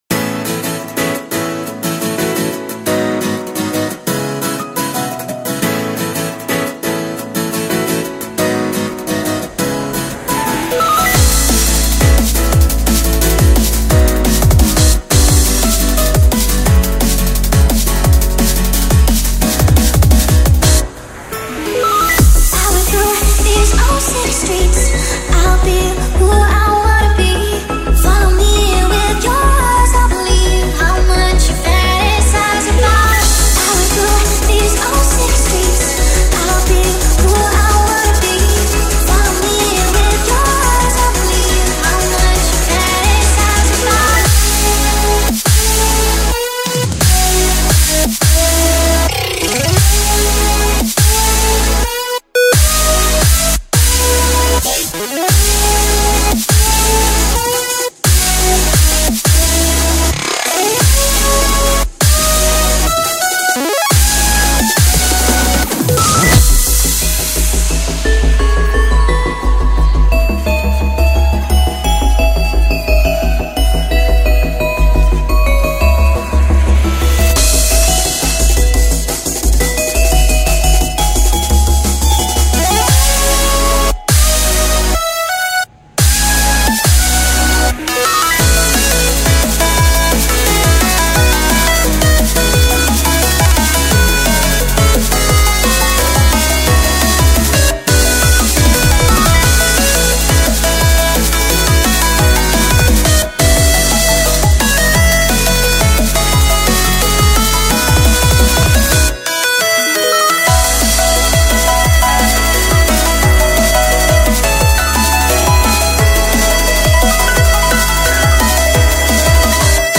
BPM87-174
MP3 QualityMusic Cut